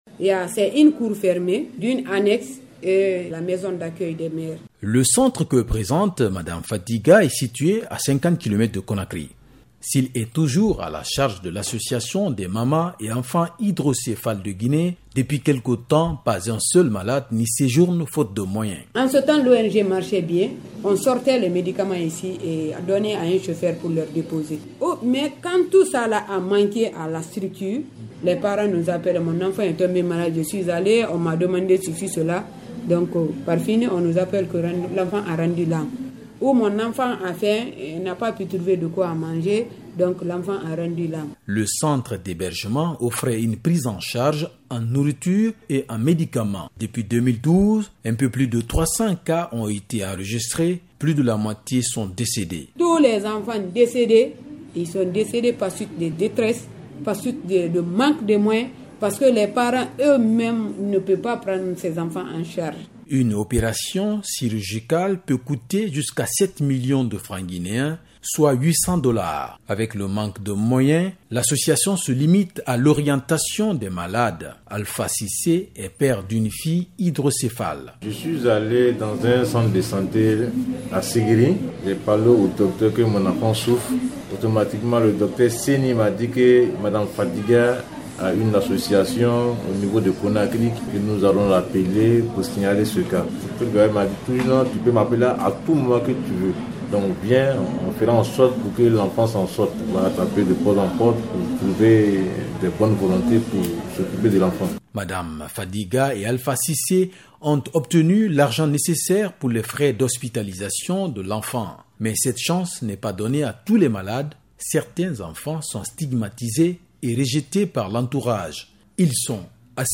Notre correspondant à Conakry s'est rendu dans un centre d'hébergement de l'Association des Mamans d'Enfants Hydrocéphales de Guinée.
Reportage